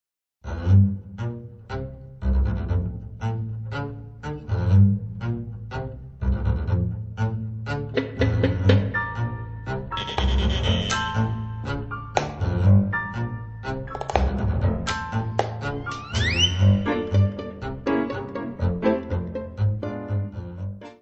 piano, congas
violino
contrabaixo
Bandoneón
tamborim, triângulo
violoncelo.
: stereo; 12 cm
Music Category/Genre:  World and Traditional Music